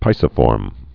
(pīsĭ-fôrm, pĭsĭ-)